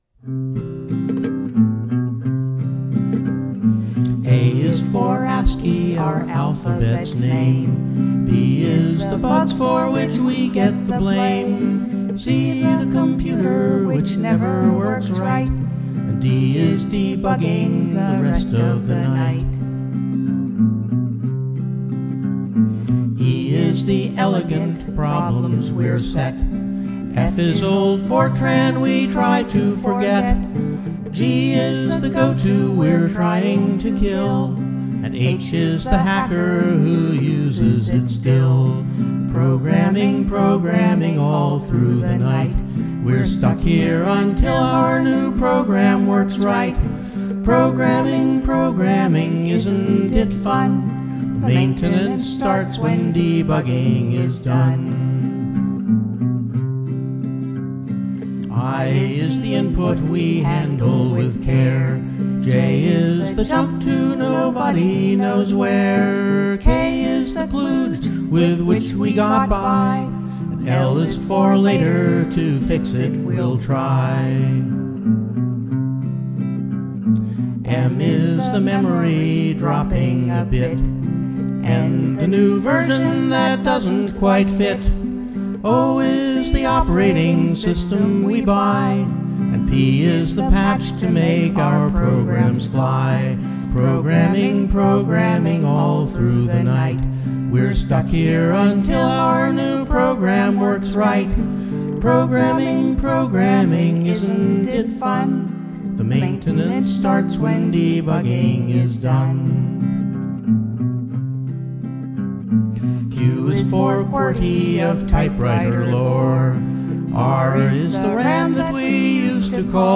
They're not necessarily in any shape to be heard!